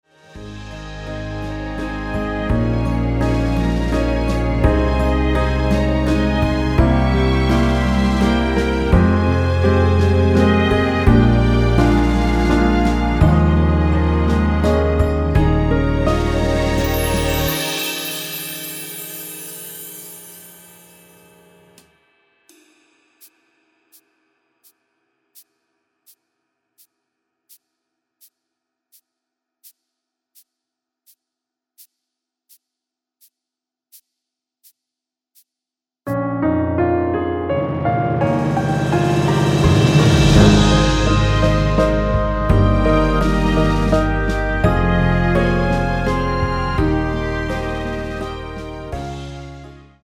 무반주 구간 들어가는 부분과 박자 맞출수 있게 쉐이커로 약하게 박자 넣어 놓았습니다.